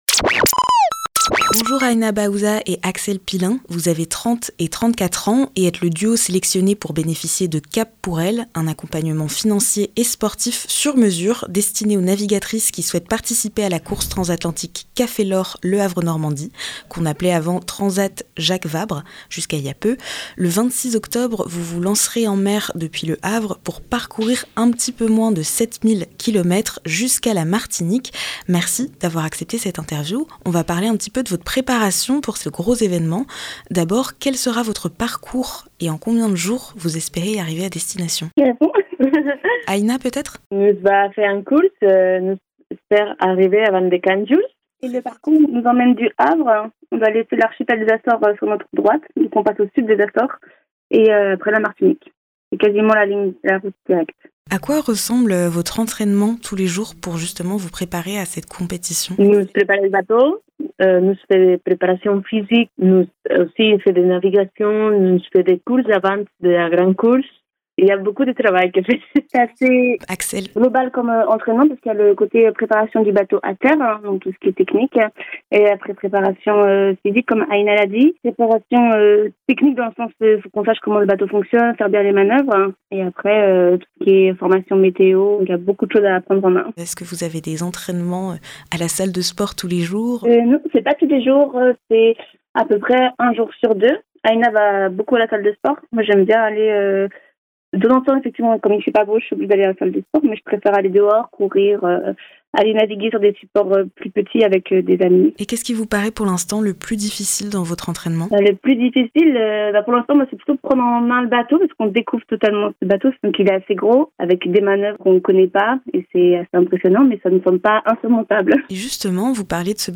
Traverser l'Atlantique : Interview